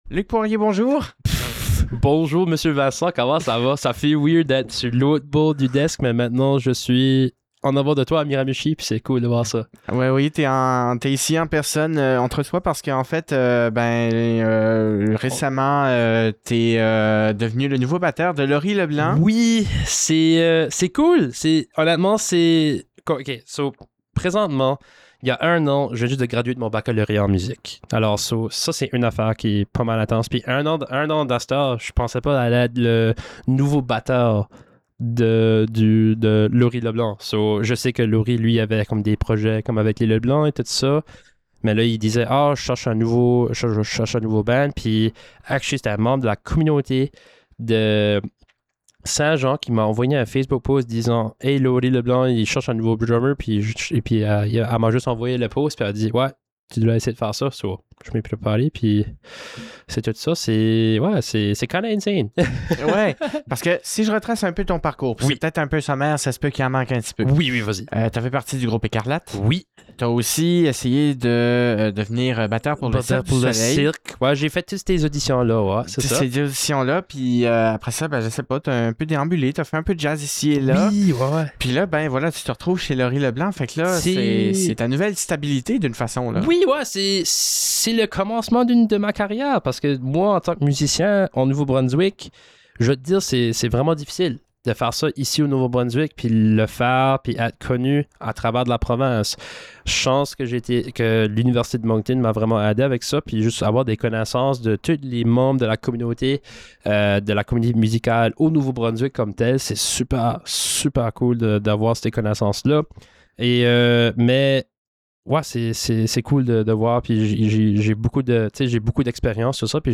Une entrevue